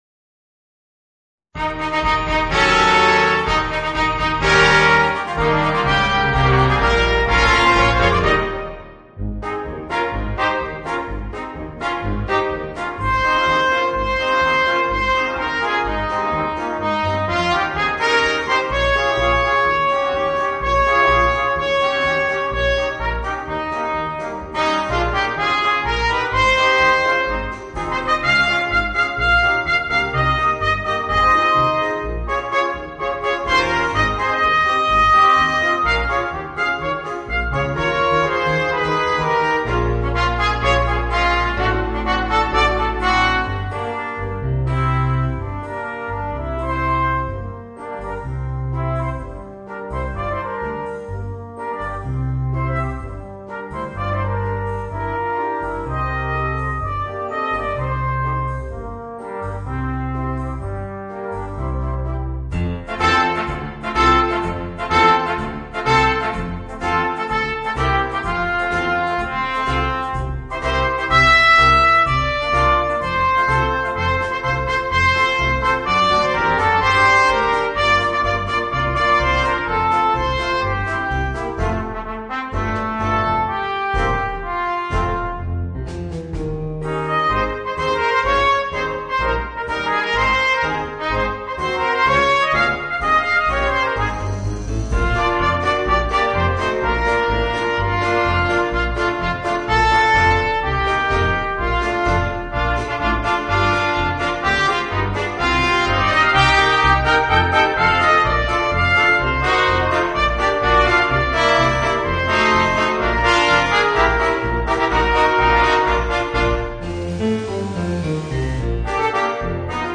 Voicing: 2 Trumpets, Horn and Trombone